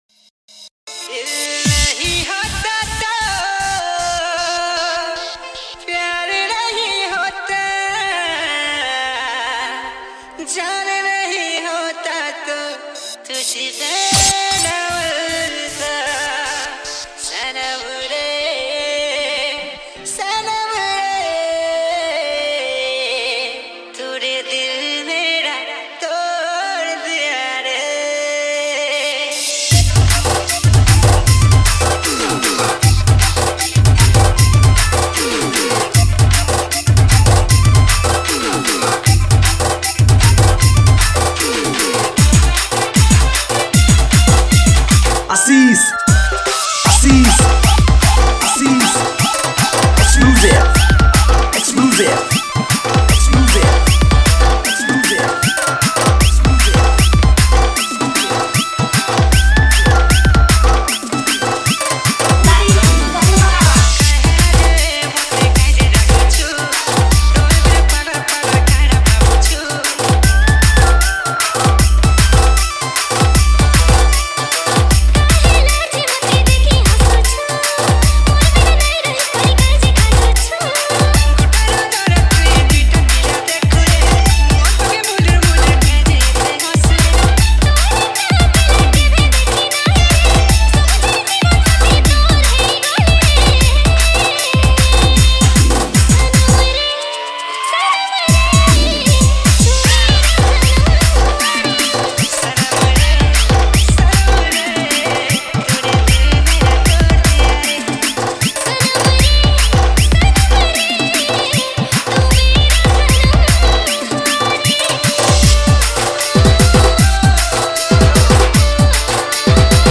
Category:  New Sambalpuri Dj Song 2020